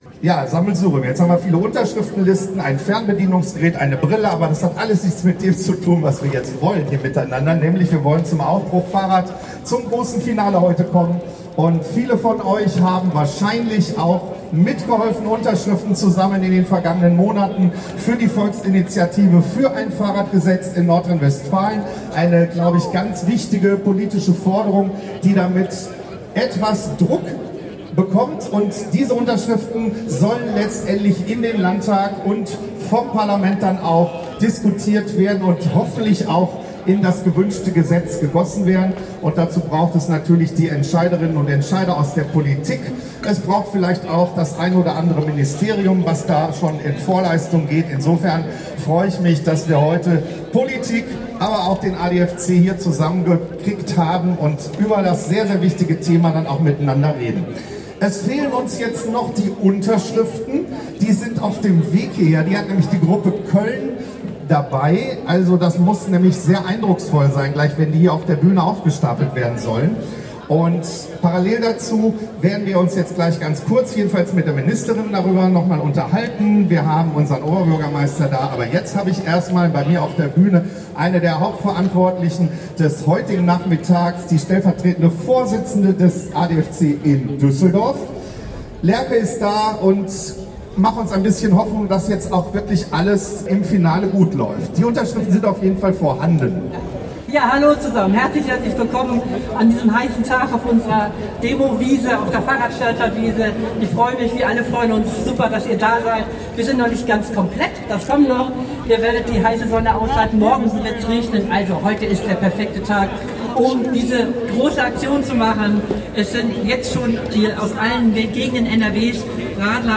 Kapitel 2: Kundgebung und Unterschriftenübergabe
Die Reden rund um das Anliegen der Volksinitiative „Aufbruch Fahrrad“